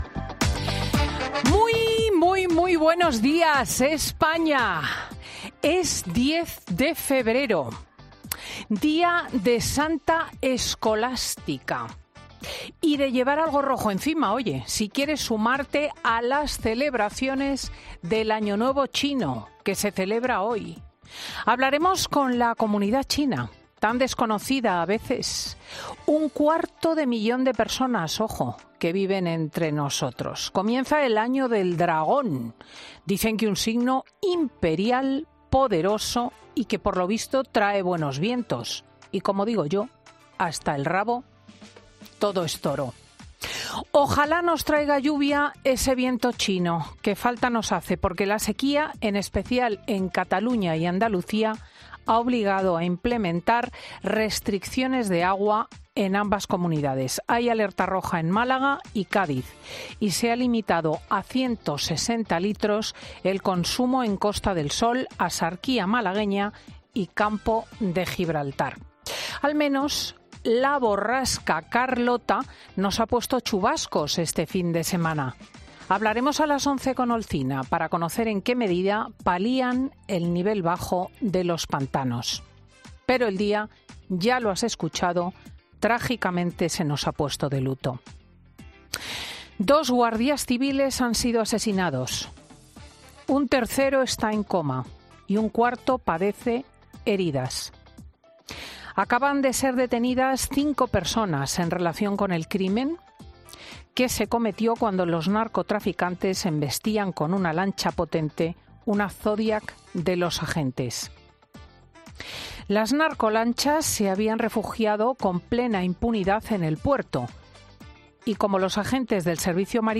AUDIO: La directora de 'Fin de Semana' analiza las principales noticias de este sábado 10 de febrero